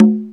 727 Conga Lo.wav